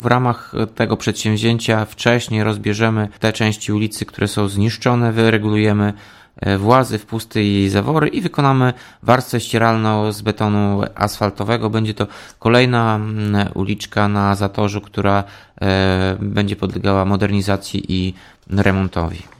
Remont potrwa do końca sierpnia – zapowiada Tomasz Andrukiewicz, prezydent Ełku.